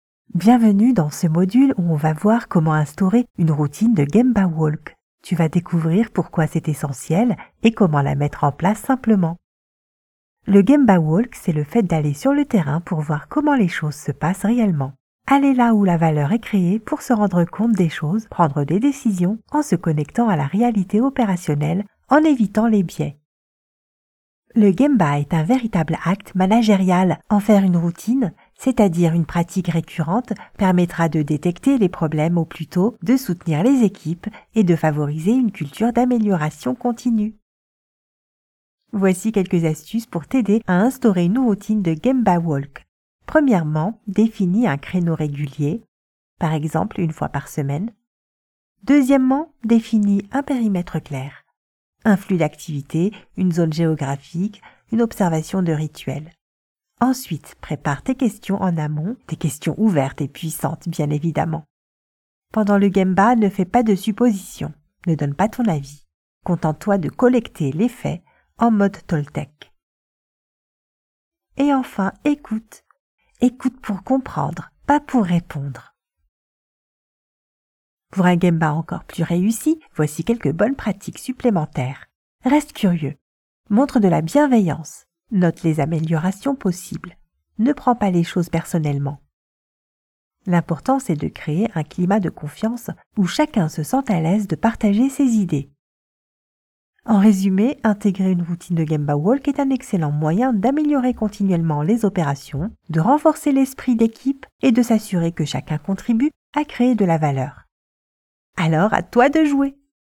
A friendly tenor voice performer in LATAM neutral, Mexican accent and English with Hispanic accent. Listen to an authentic, confident, and energetic voice ready for your projects....
0308demo-elearning.mp3